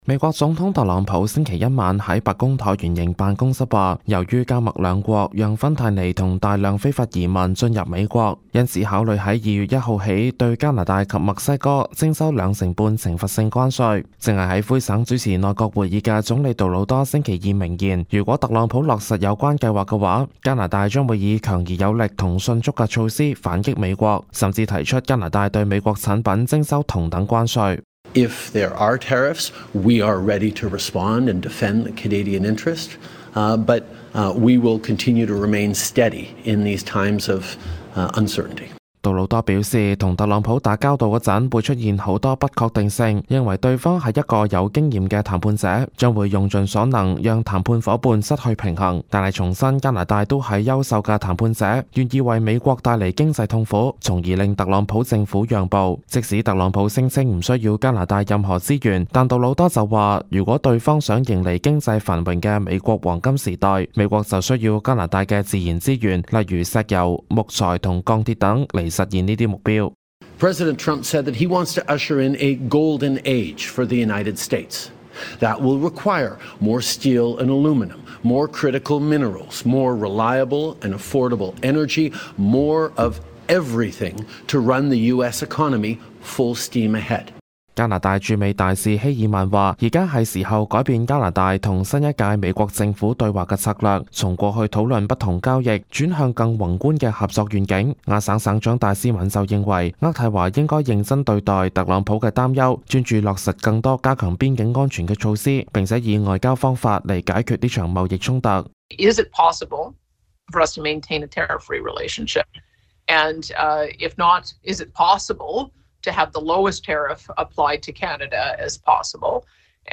報道